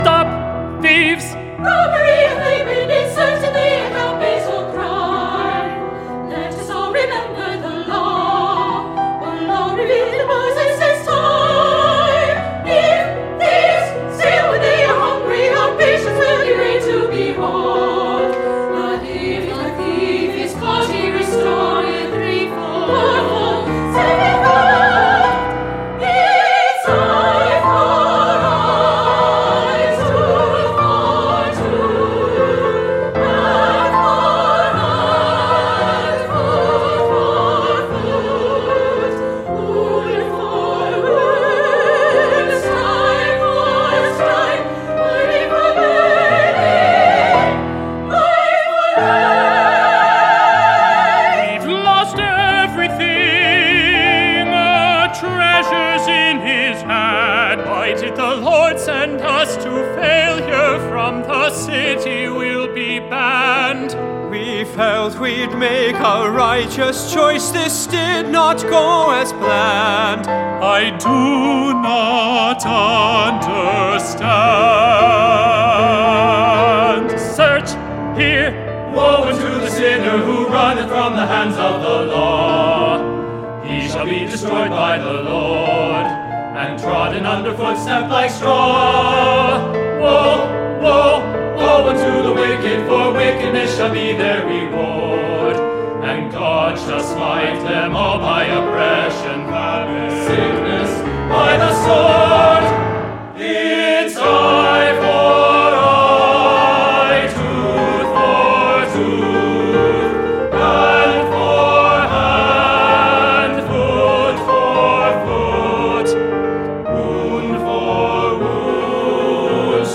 Opera Chorus
Piano OR Fl, Ob, Cl, Bn, Hn, Tr, Perc, Hp, Pno, and Str
Full ensemble